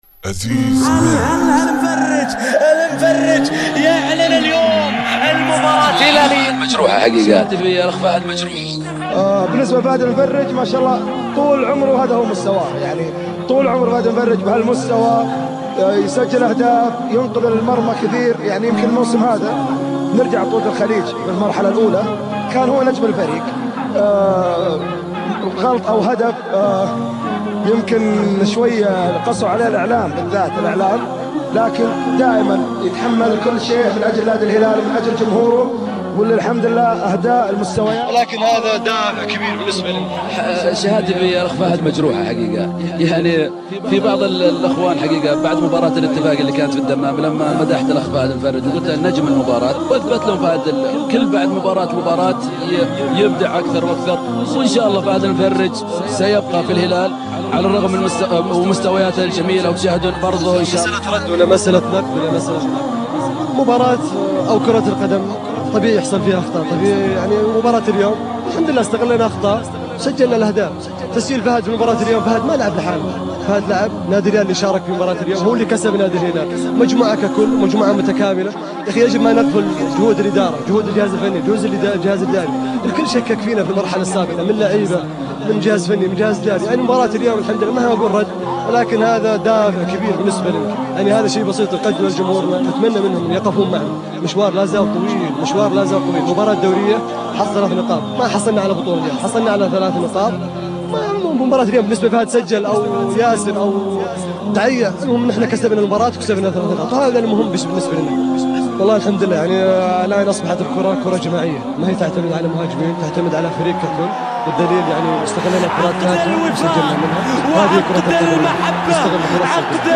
ريمكس للمبدعـ